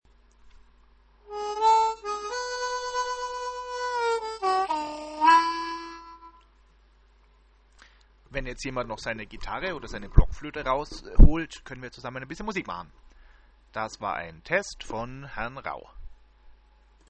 Spielt wer mit? (E-Dur.)